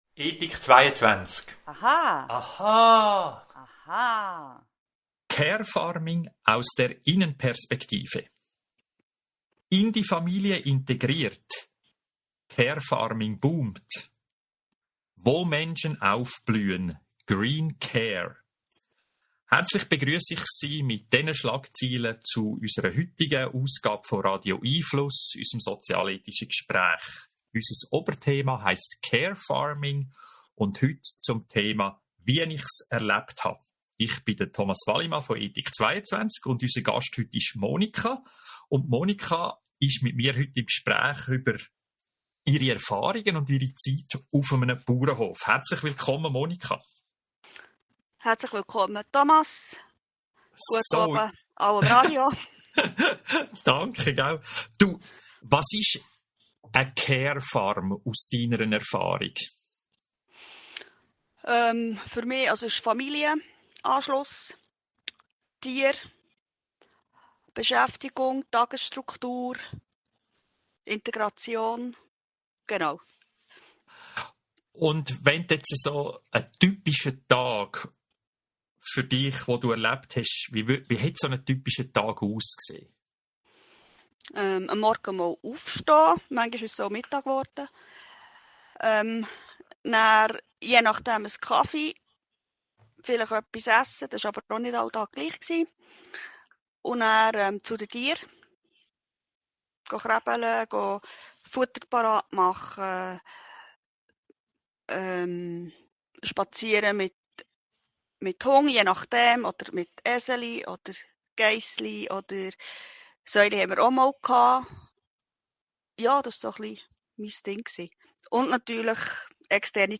zum Thema hören Sie im Podcast unseres Gesprächs vom 8. Mai 2024